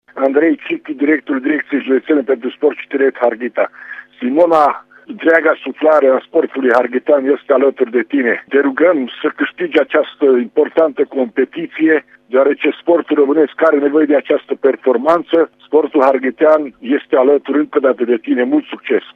O dovedesc mesajele oamenilor de sport din județele Mureș și Harghita: